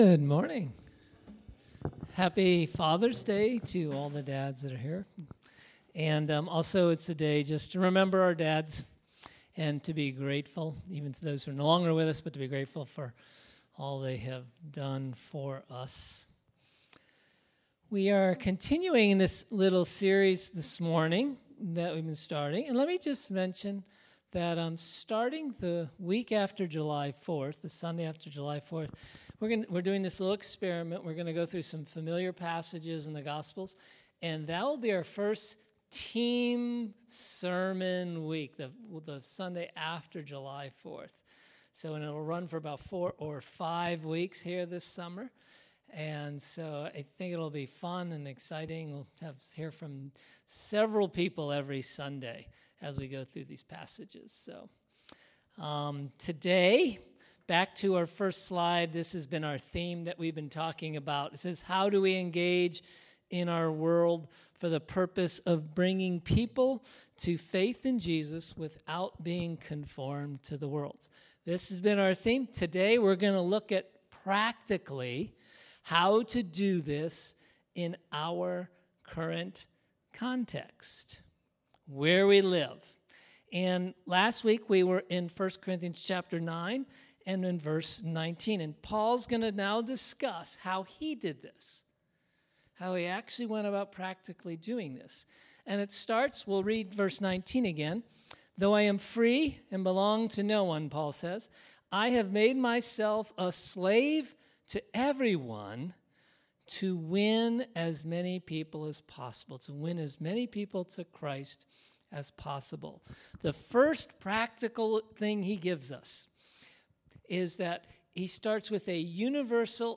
Grace Summit Community Church | Cuyahoga Falls, Ohio